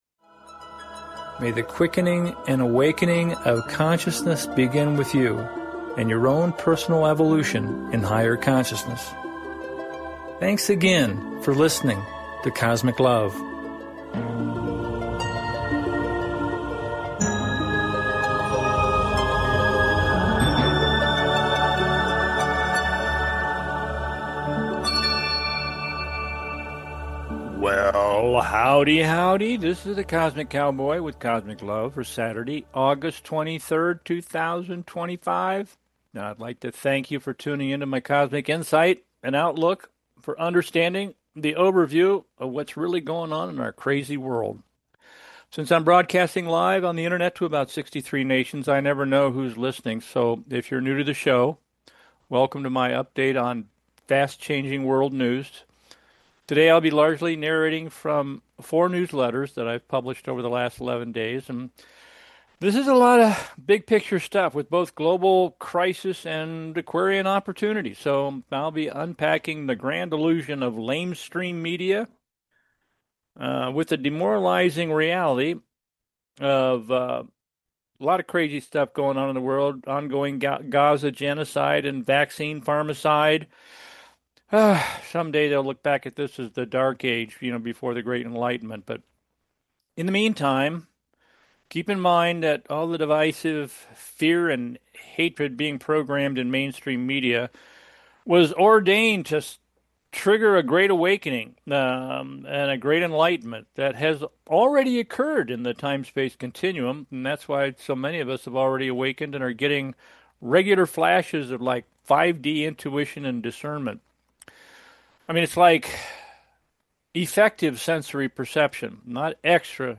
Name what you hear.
Discussing these recent newsletters: